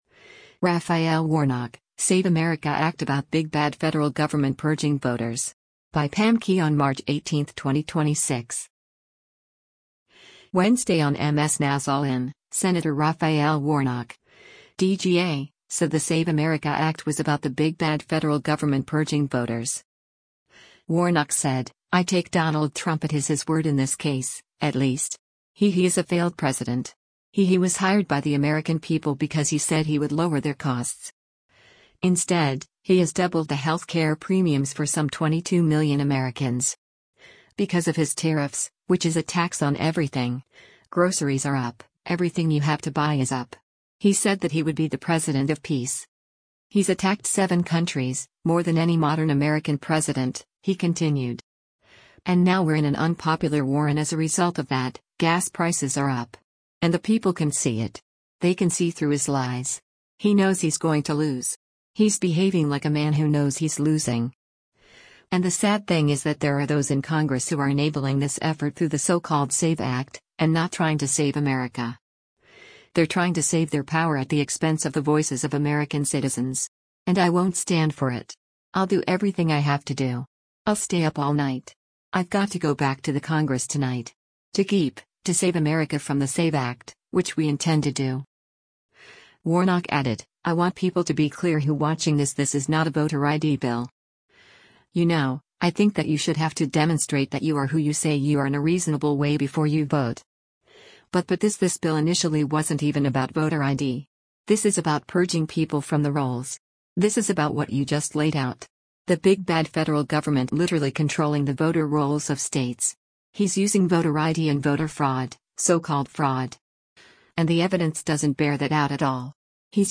Wednesday on MS NOW’s “All In,” Sen. Raphael Warnock (D-GA) said the SAVE America Act was about the “big bad federal government” purging voters.